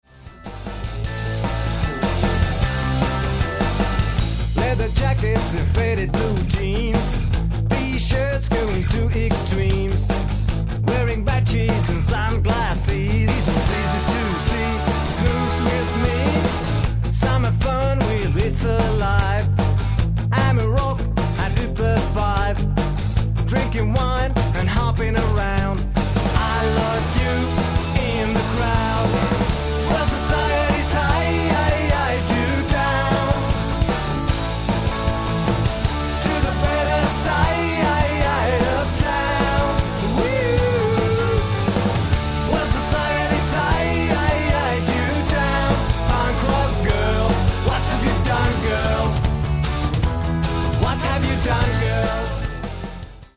(Compressed/Mono 418kb)